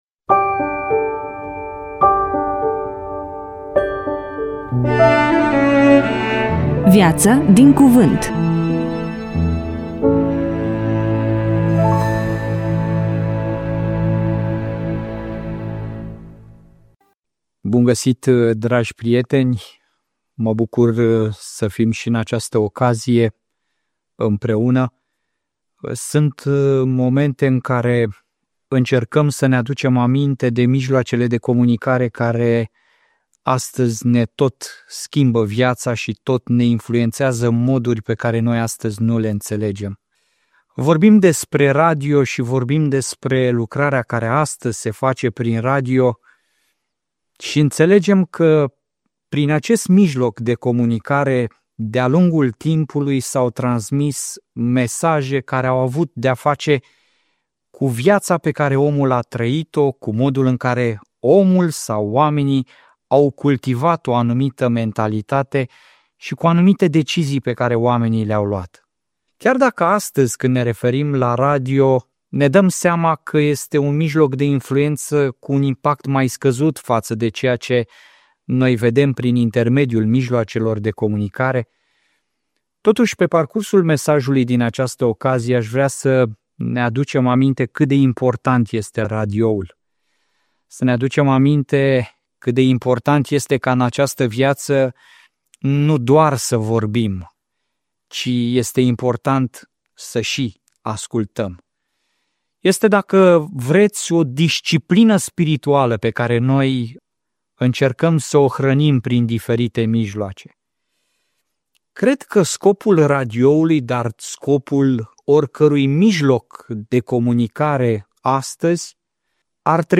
EMISIUNEA: Predică DATA INREGISTRARII: 21.02.2026 VIZUALIZARI: 11